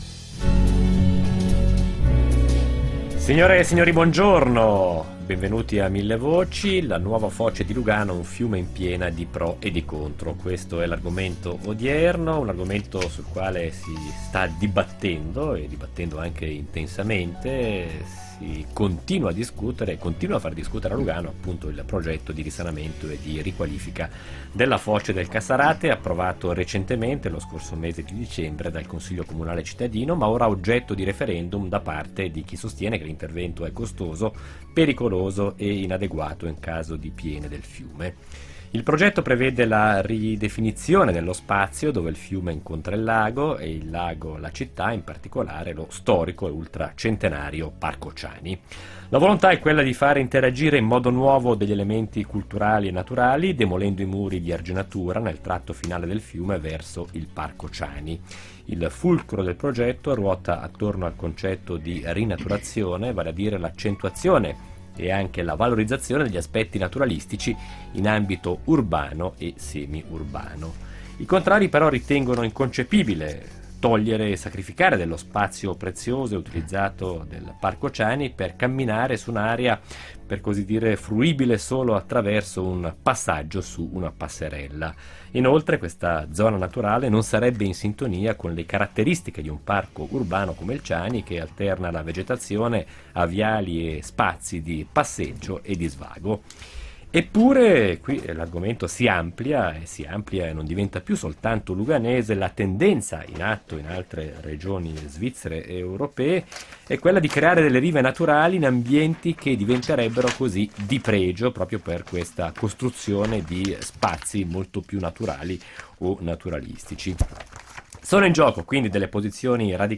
Millevoci